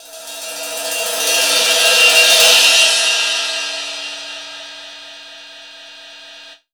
H34CYMB.wav